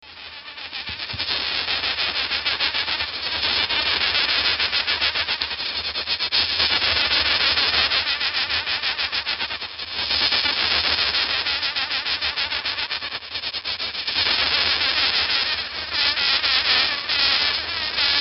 sceliphron2.mp3